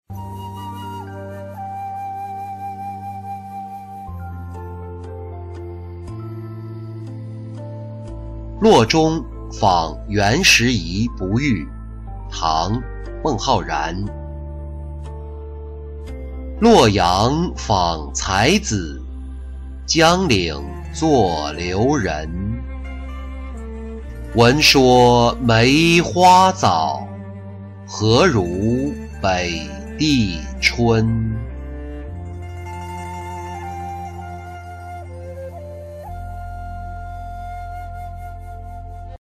洛中访袁拾遗不遇-音频朗读